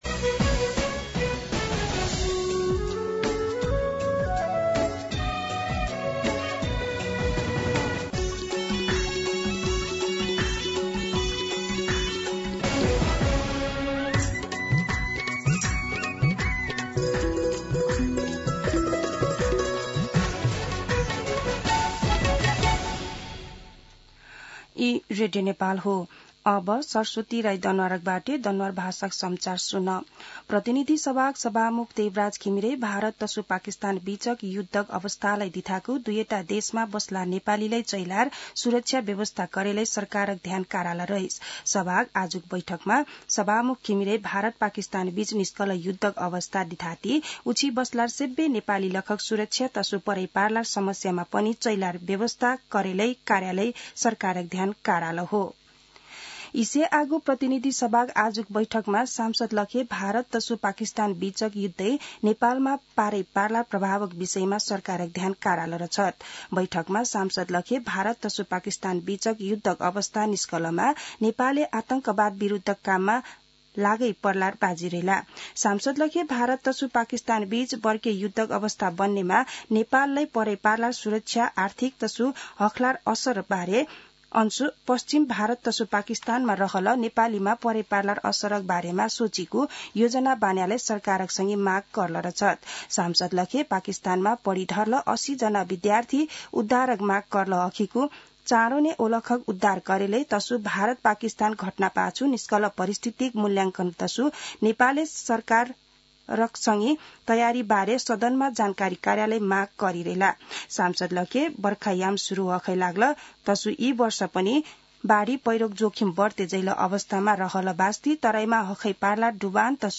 दनुवार भाषामा समाचार : २६ वैशाख , २०८२